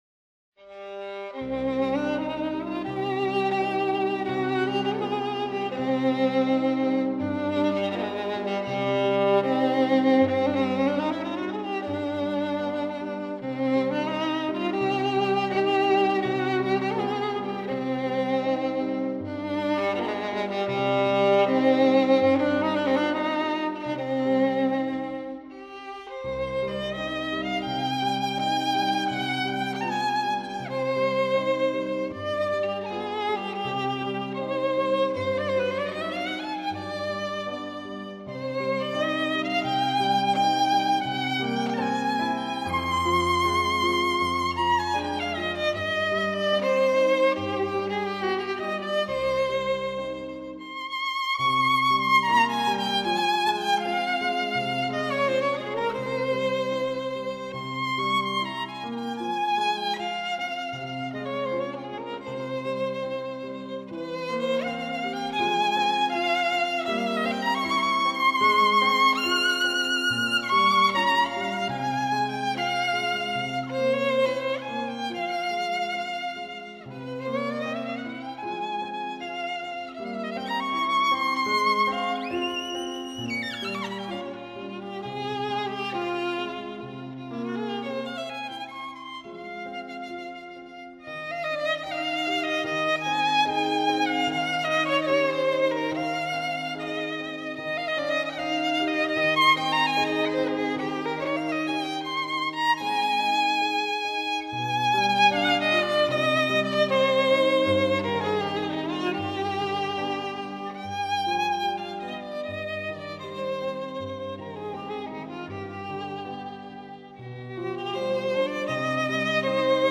无论从演技到音质都无可挑剔，曲调优美，演奏细腻，丝丝入扣，是发烧友不可多得的一张试音天碟。